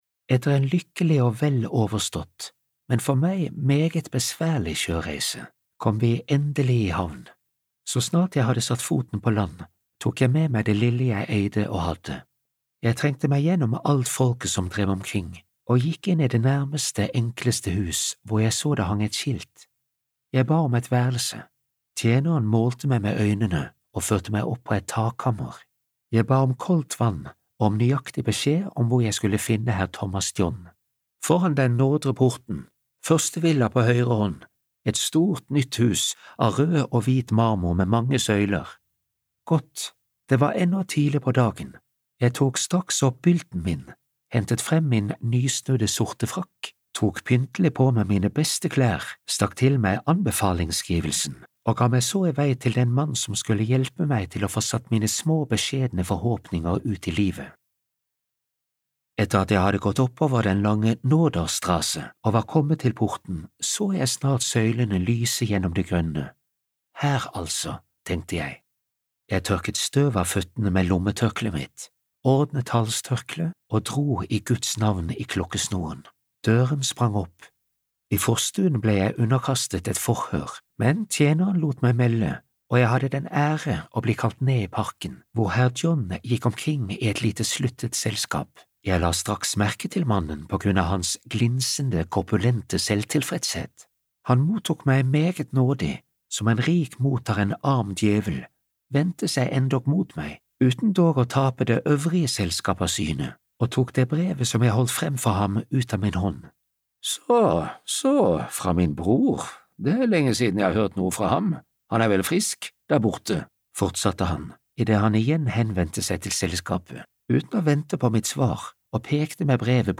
Peter Schlemihls forunderlige historie (lydbok) av Adelbert von Chamisso